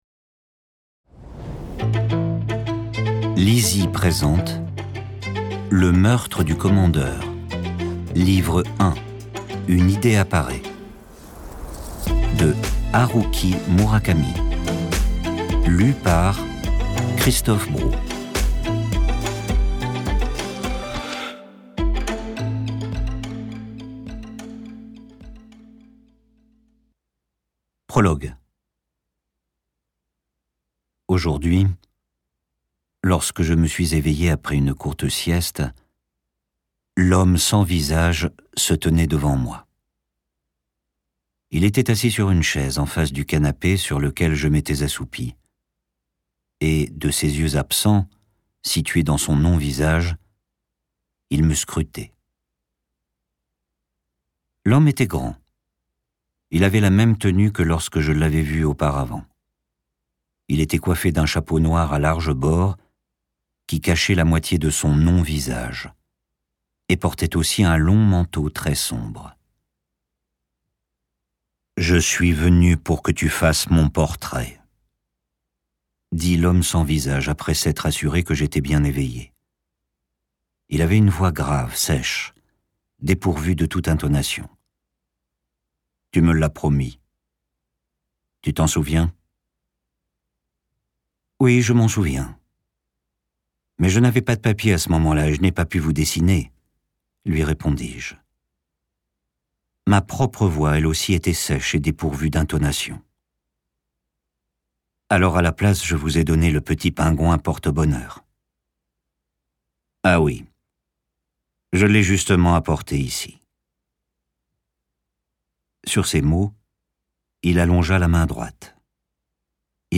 Click for an excerpt - Le Meurtre du Commandeur de Haruki MURAKAMI